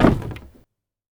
wood_place.ogg